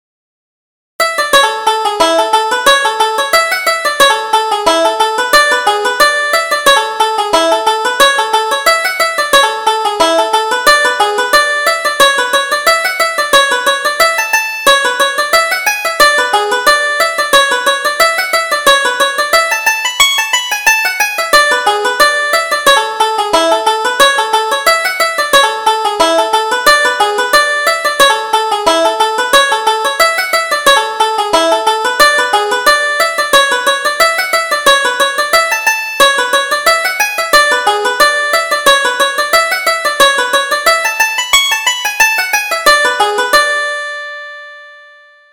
Reel: Little Katey Kearney